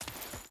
Dirt Chain Walk 3.ogg